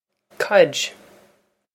caid codge
This is an approximate phonetic pronunciation of the phrase.